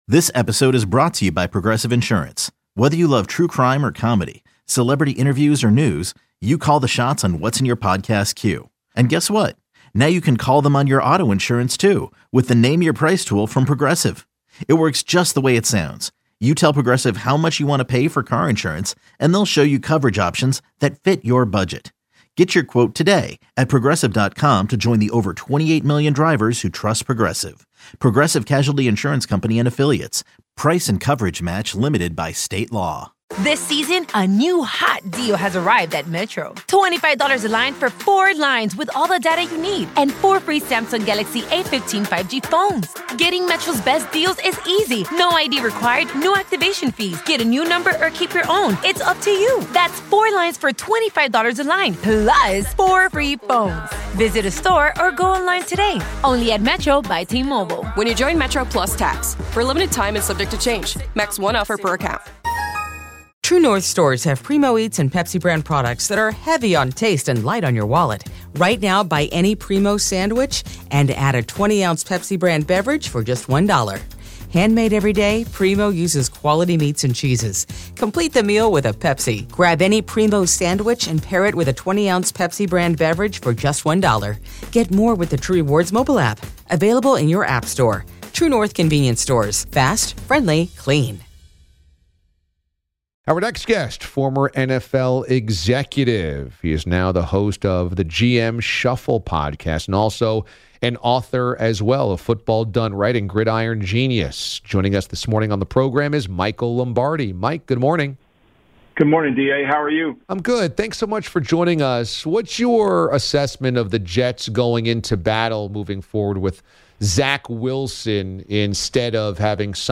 Former NFL Executive, Author, and host of "The GM Shuffle" podcast, Mike Lombardi, joins DA to talk about all things NFL including the Jets QB situation, the debate over Dak Prescott, and the Bengals disappointing week one.